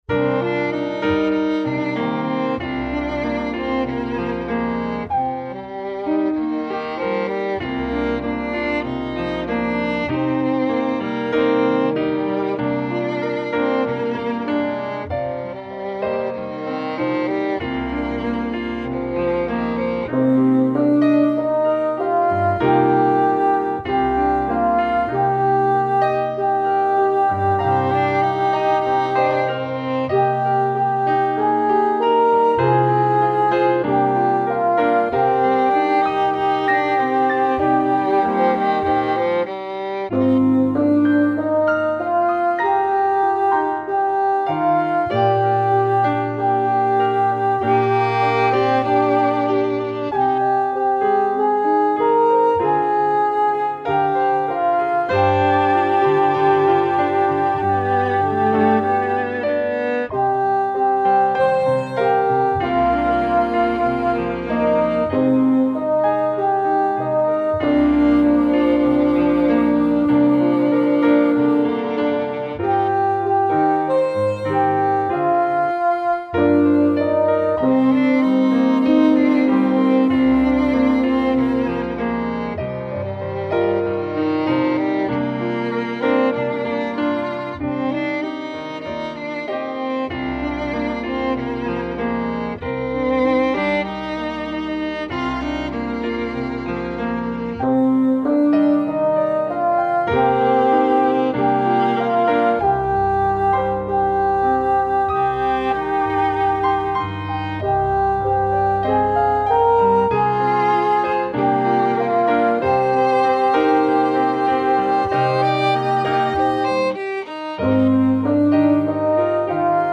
My backing does that.